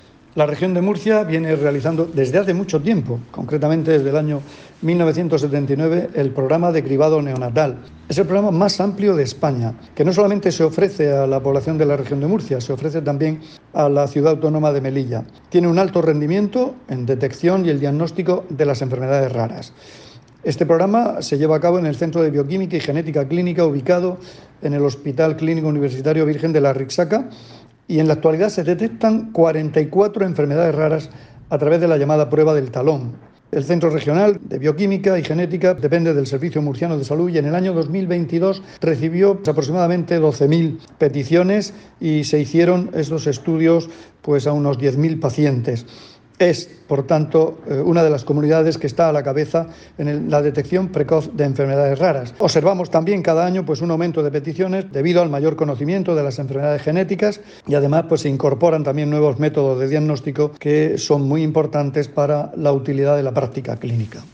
Declaraciones del consejero de Salud, Juan Jose Pedreño, sobre las pruebas de cribado neonatal que se realizan en la Región de Murcia.